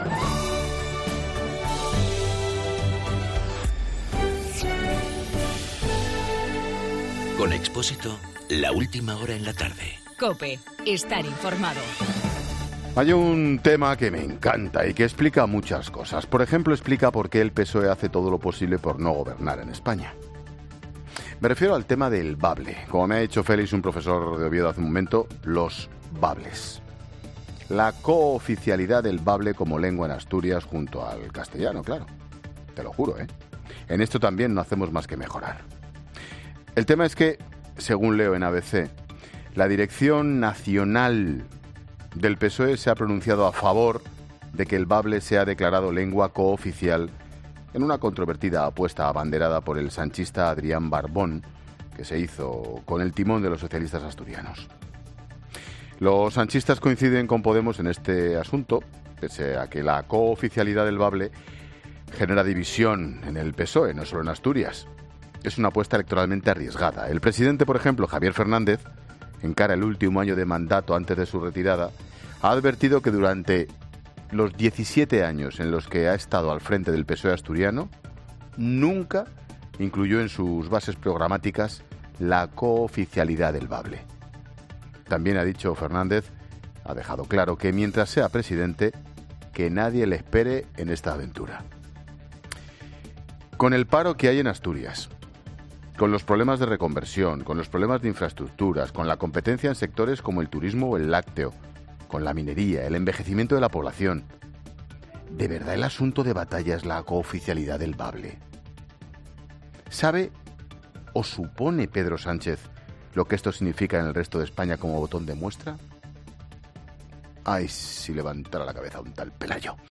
AUDIO: El comentario de Ángel Expósito sobre la propuesta del PSOE.